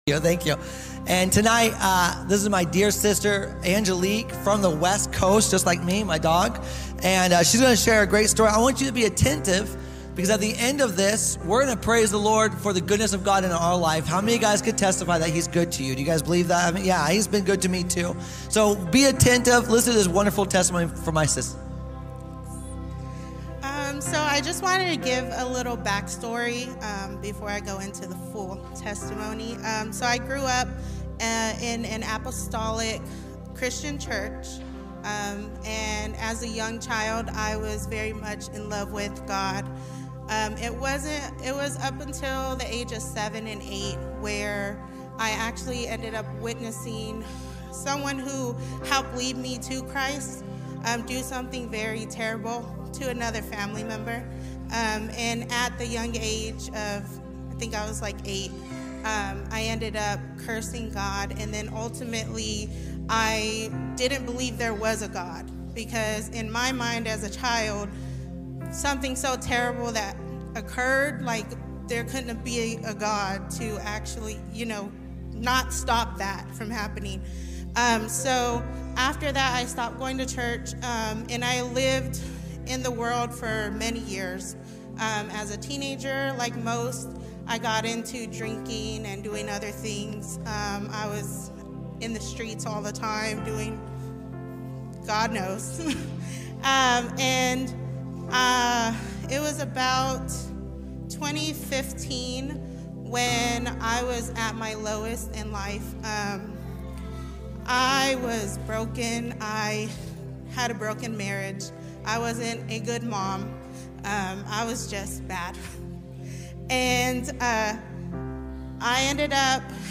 preaches on the power of the Holy Spirit and the marking of the new covenant